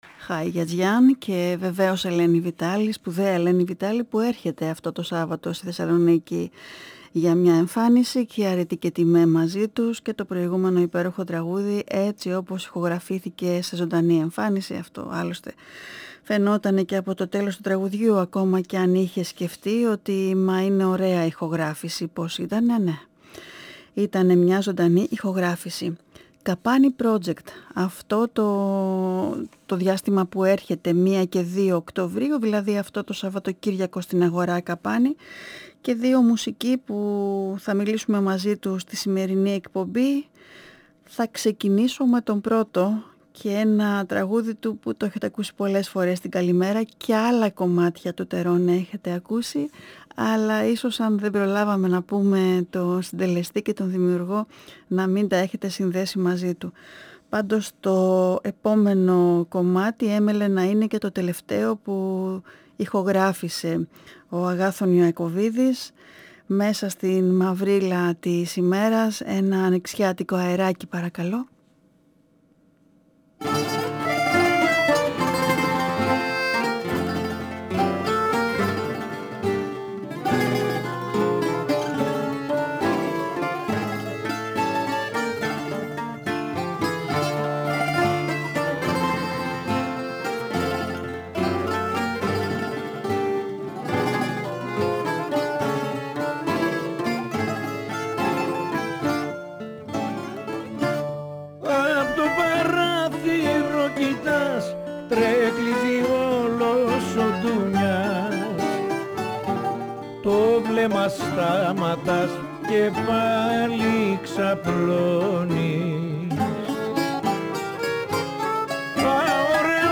1 & 2 Οκτωβρίου στην Θεσσαλονίκη Η συνέντευξη πραγματοποιήθηκε τη Δευτέρα 26 Σεπτεμβρίου 2022 στην εκπομπή “Καλημέρα” στον 9,58fm της ΕΡΤ.